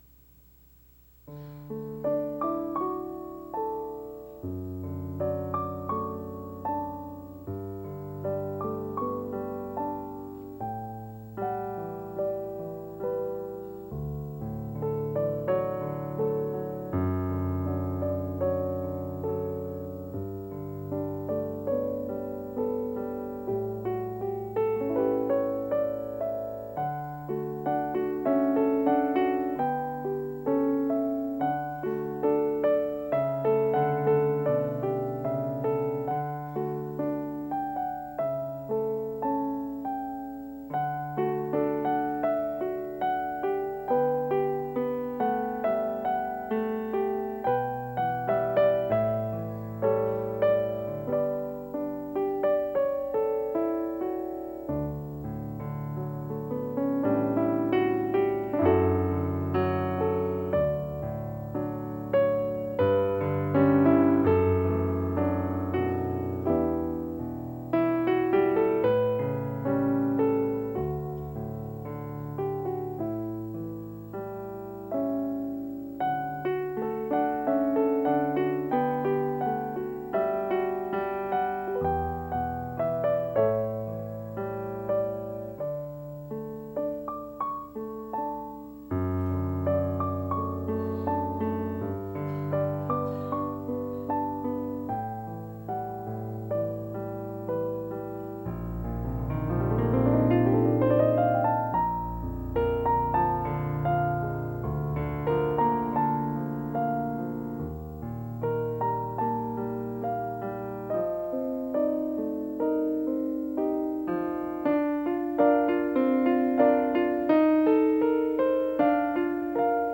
at Faith Baptist Church last Sunday.